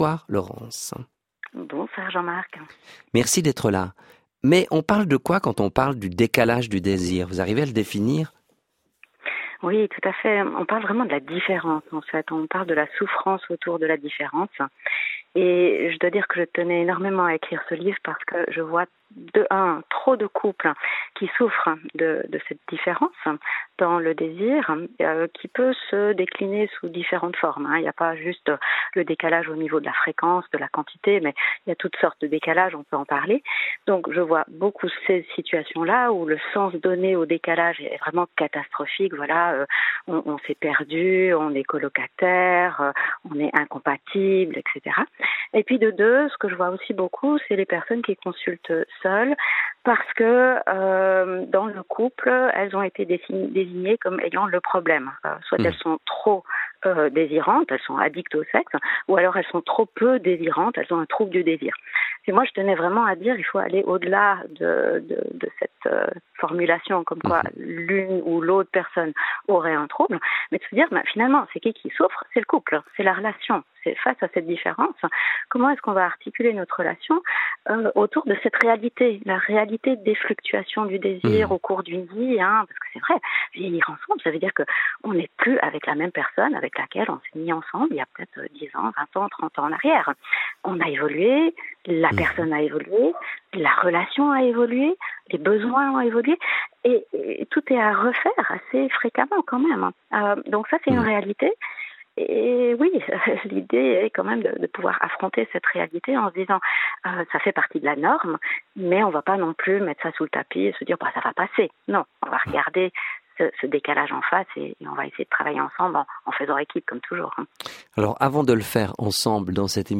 Interview à la RTS (La ligne de coeur) 5.12.24